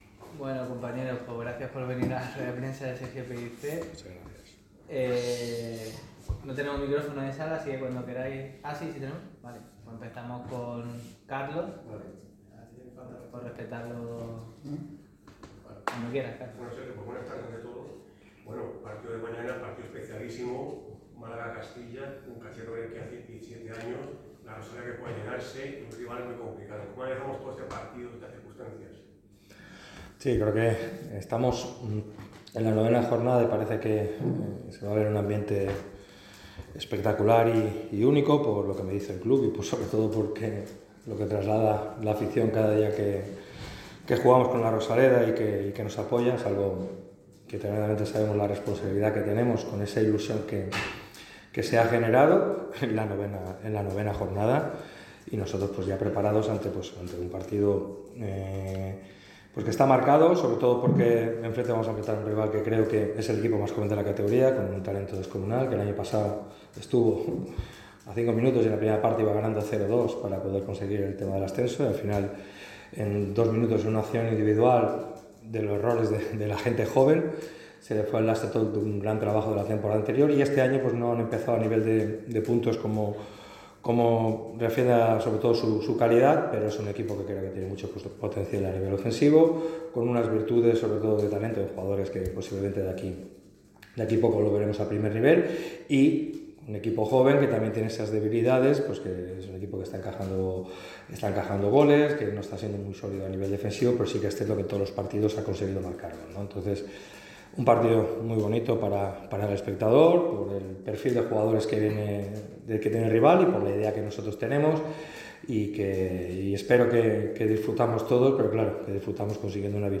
El técnico malaguista habló ante los medios de comunicación en la previa del choque ante el Real Madrid Castilla. Pellicer y la «magia» del área que tiene el nombre y los apellidos de Dioni Villalba.